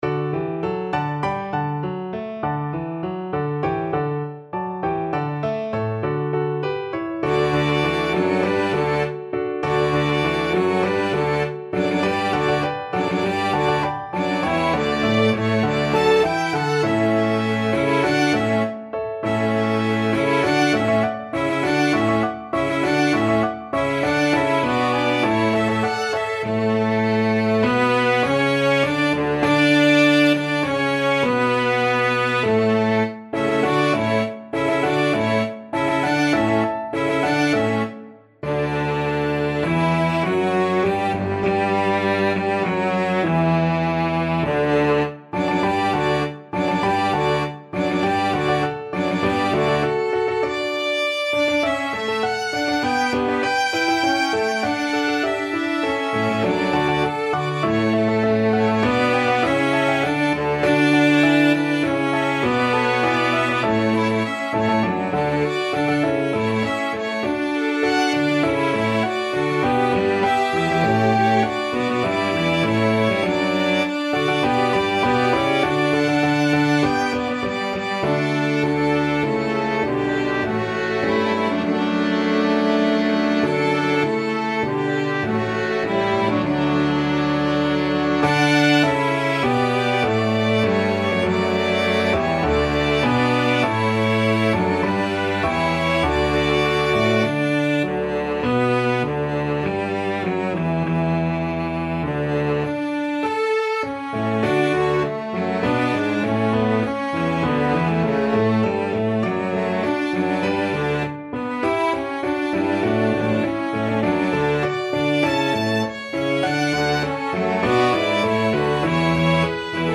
Classical
Allegro (View more music marked Allegro)
4/4 (View more 4/4 Music)